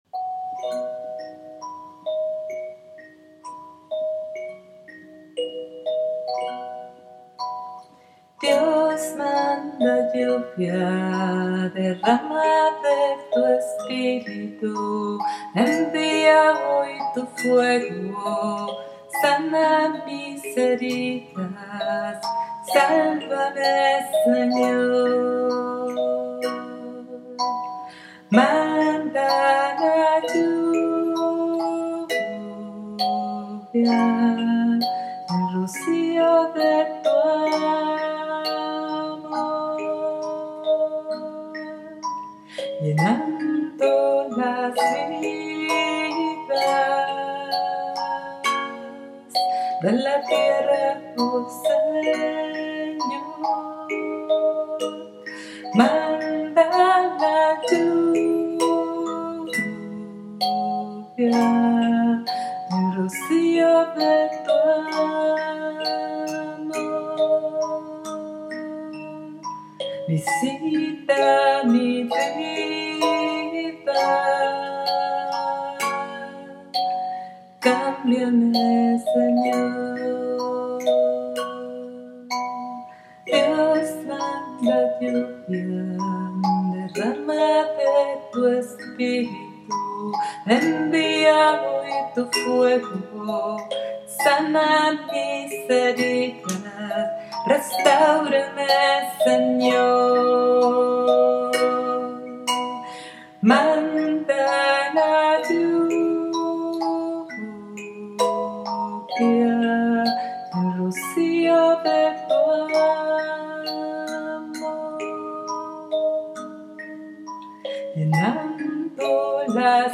CANCION (Invocación al Espiritu Santo)